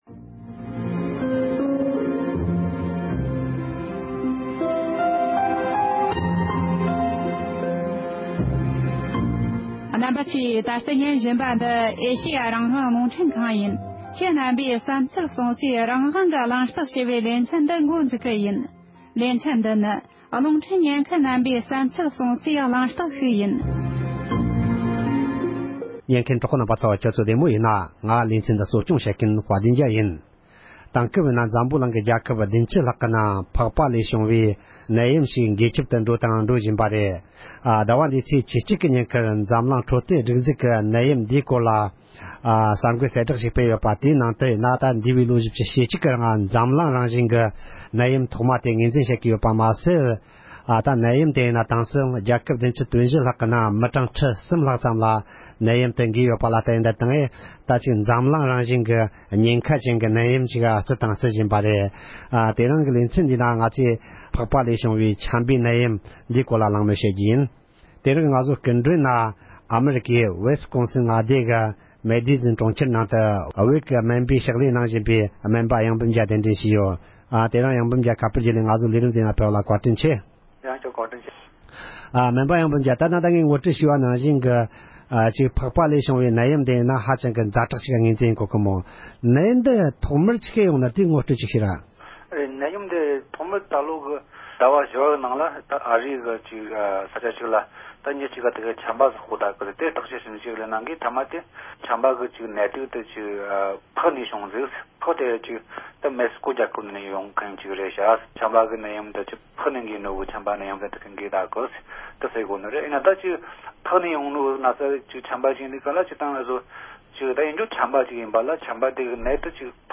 འཛམ་གླིང་ནང་ཁྱབ་གདལ་འགྲོ་བཞིན་པའི་ཕག་པ་ལས་བྱུང་བའི་ཆམ་ནད་ཨེཅ་བན་ཨེན་བན་ཞེས་པའི་སྐོར་གླེང་མོལ།